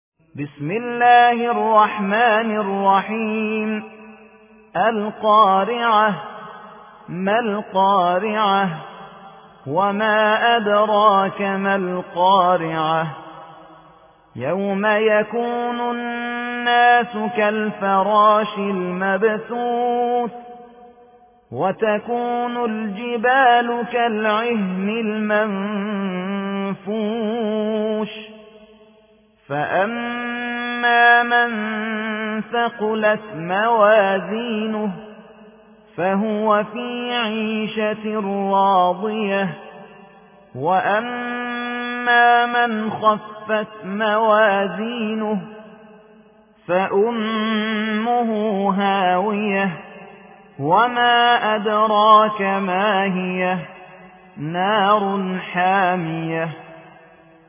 101. سورة القارعة / القارئ